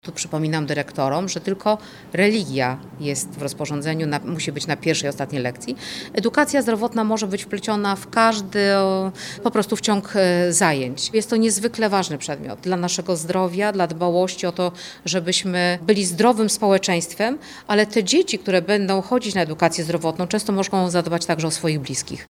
Jak tłumaczy kurator oświaty wbrew pierwotnym zapowiedziom resortu, edukacja zdrowotna nie jest obowiązkowa w tym roku szkolnym.